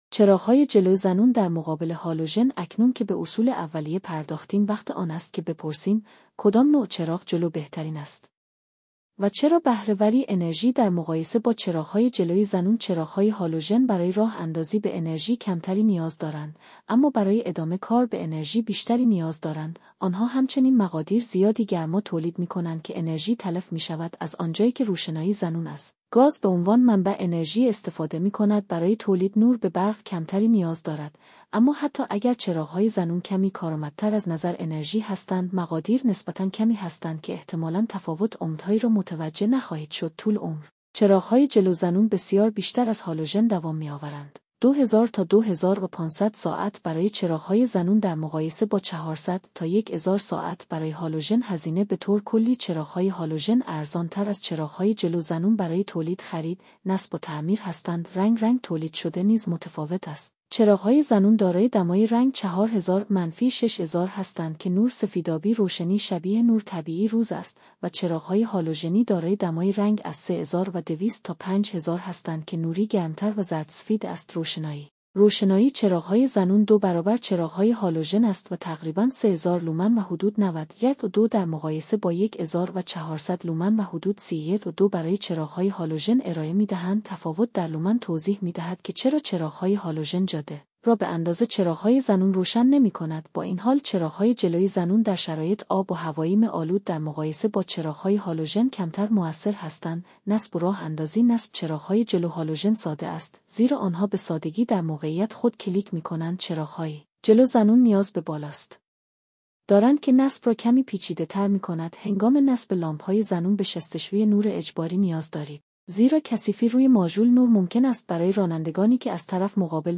میتوانید توضیحات هوش مصنوعی درباره تفاوت میان چراغ جلو هالوژن و زنون را از طریق پادکست زیر گوش کنید: